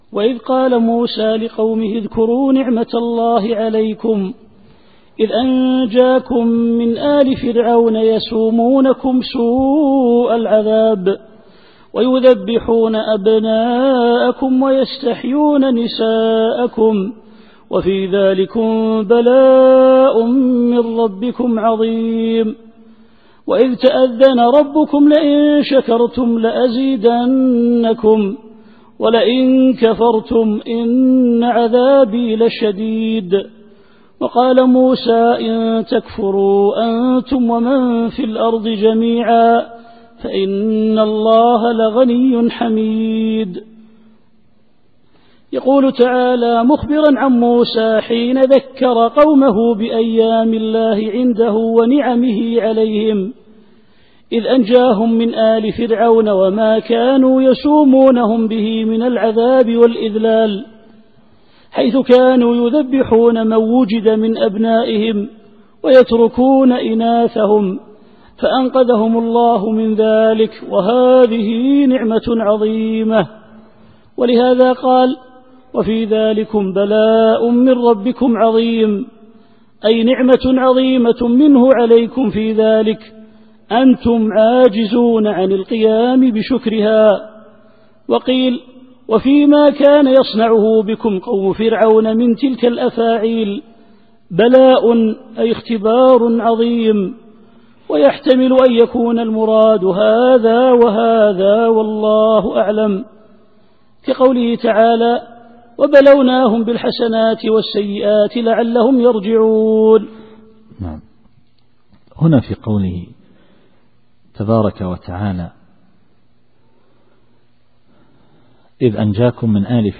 التفسير الصوتي [إبراهيم / 6]